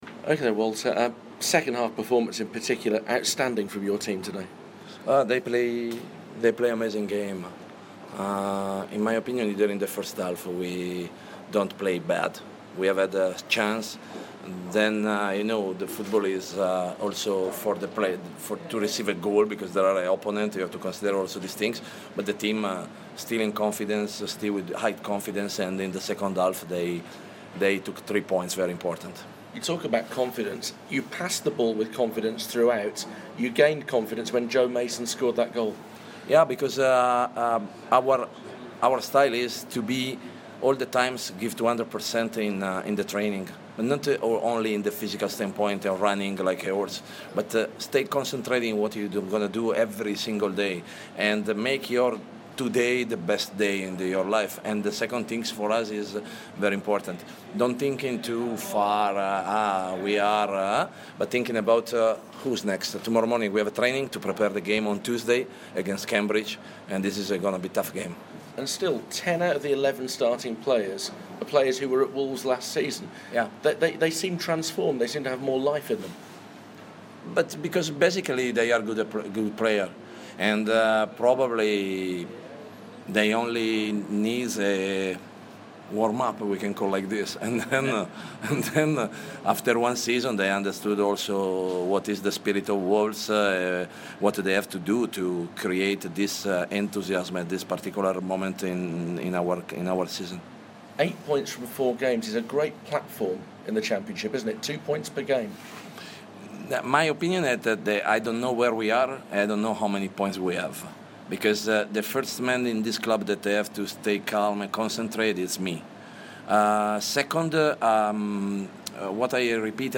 Wolves boss Walter Zenga talks to BBC WM after his side's 3-1 derby day win against Birmingham City at St Andrews.